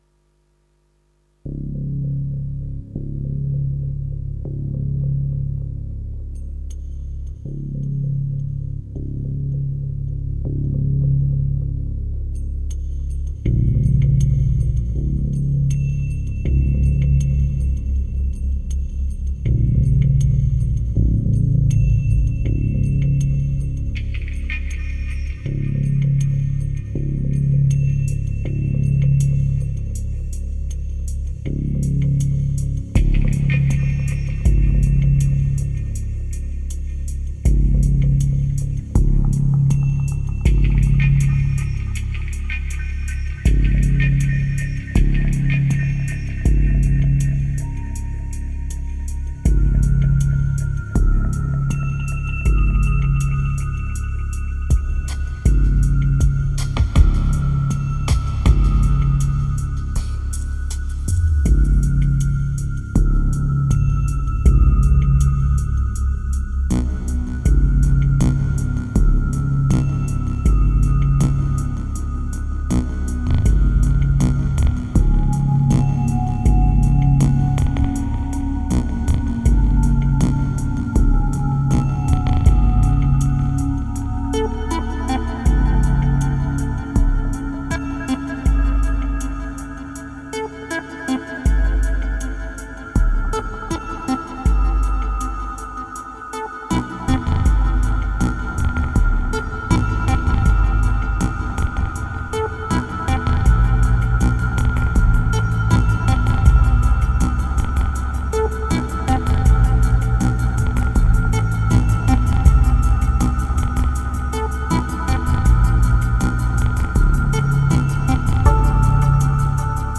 2047📈 - -7%🤔 - 80BPM🔊 - 2012-08-18📅 - -110🌟